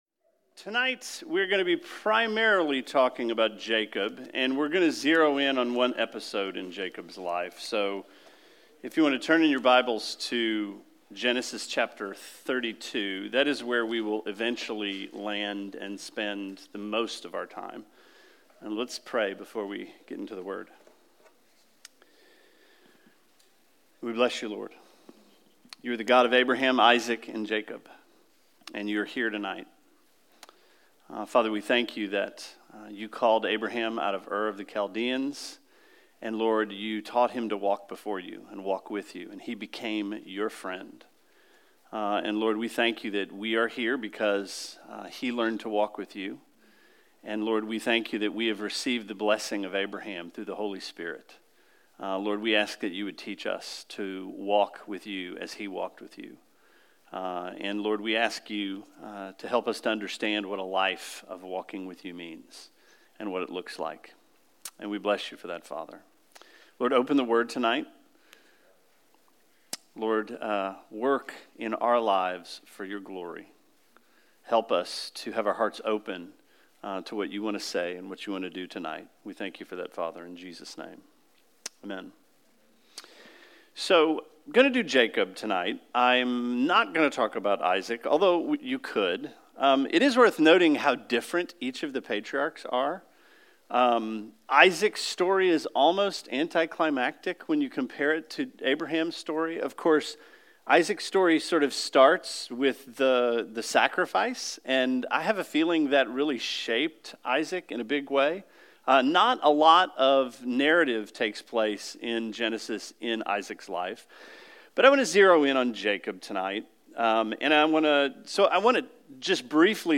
Sermon 04/05: Wrestling with God